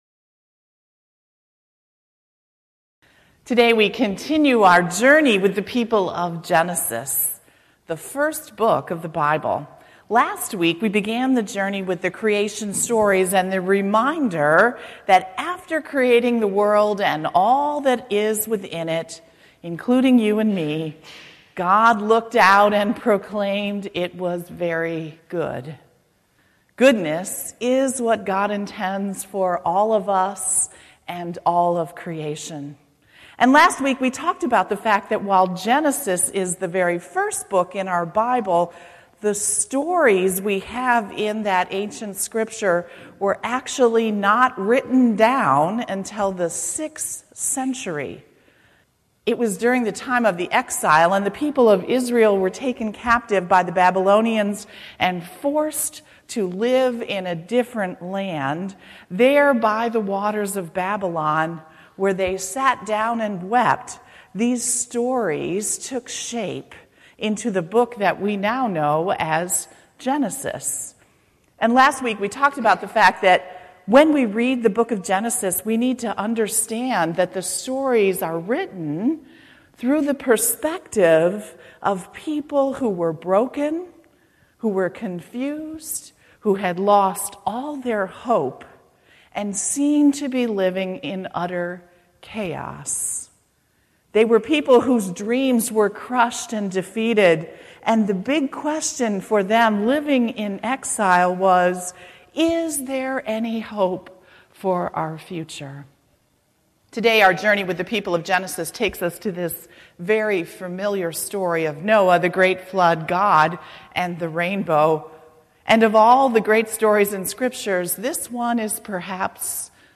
July 29, 2018 Sermon, "Rainbows and Promises" • Nardin Park Church